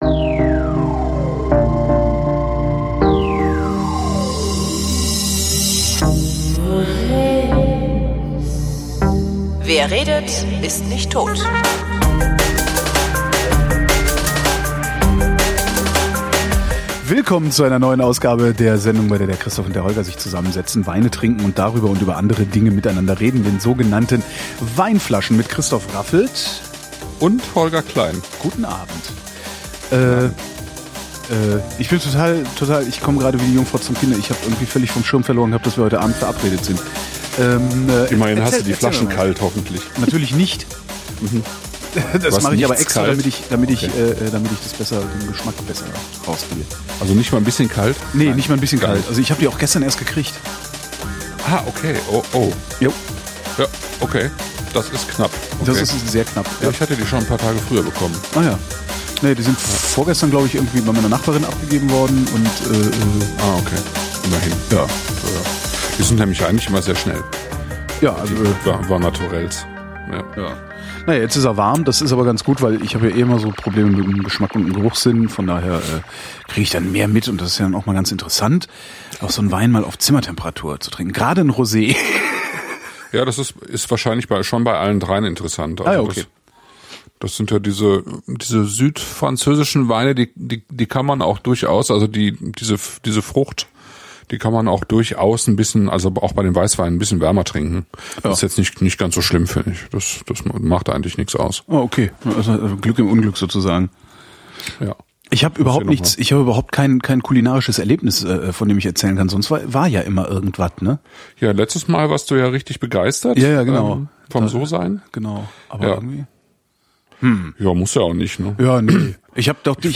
Wir saßen und redeten und tranken: Domaine Roche-Audran, Côtes du Rhône blanc 2014 – Domaine Roche-Audran, Rosé “Les Dentelles du Cygne” 2015 – Domaine Roche-Audran, Côtes du Rhône Villages Visan Rouge 2014.